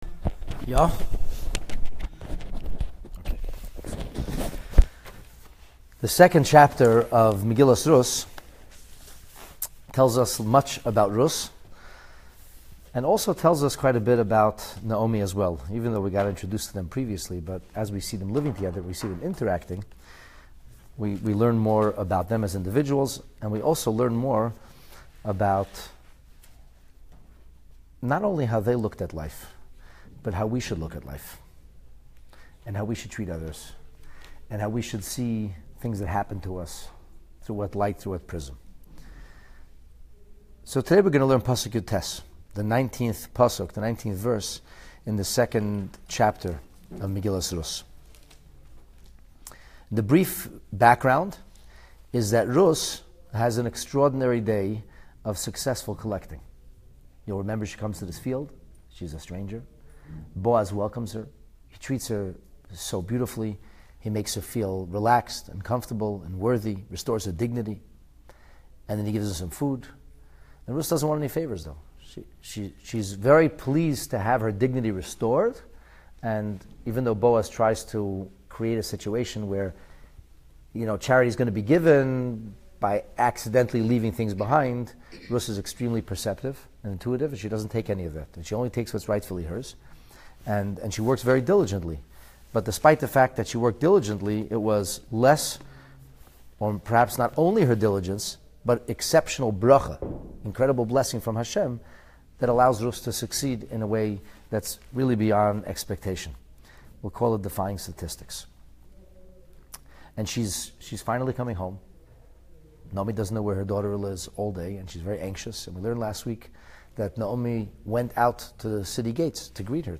Megillat Rut in Depth: Chapter 2, Lesson 8 (PT 16) Ruth’s Field Report. Ruth’s safe return relieves Naomi, yet she anxiously inquires about her daughter-in-law's surprising success; who in turn excitedly reports on the day’s amazing harvest happenings. This careful study of their conversation and accompanying innuendo reveals much about both exceptional women and teaches us important life lessons!